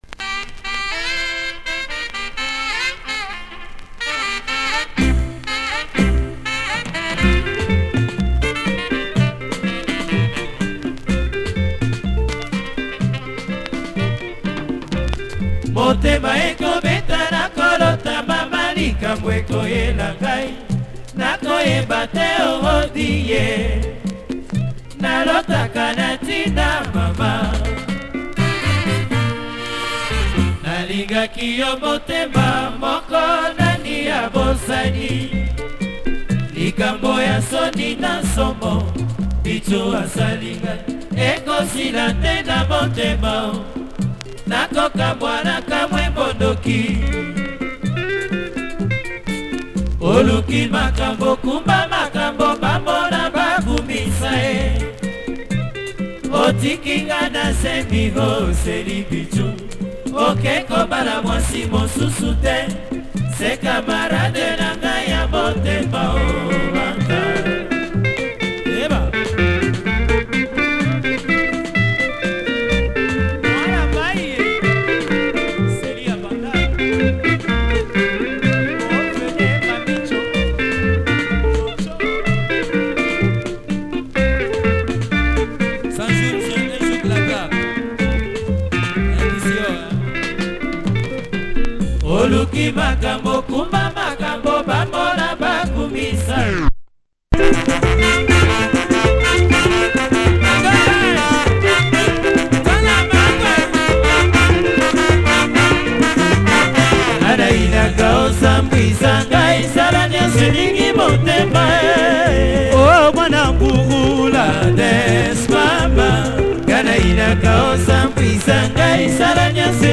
Check the actual soundsclip of this disc on both sides.